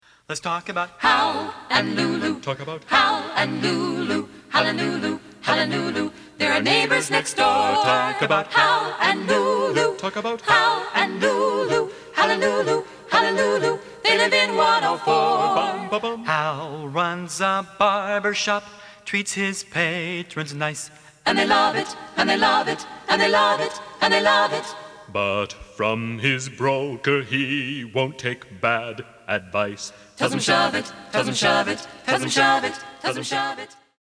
- our only STUDIO recording!